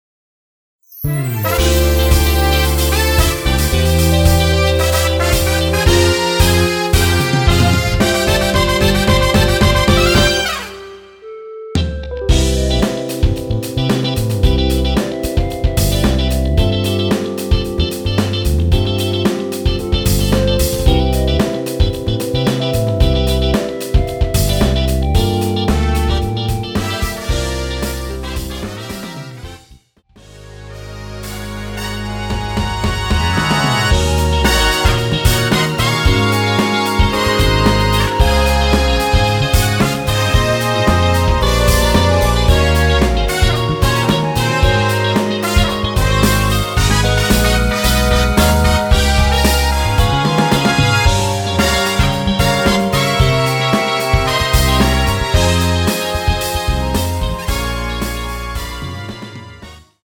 원키에서(+4)올린 멜로디 포함된 MR입니다.
멜로디 MR이라고 합니다.
앞부분30초, 뒷부분30초씩 편집해서 올려 드리고 있습니다.
중간에 음이 끈어지고 다시 나오는 이유는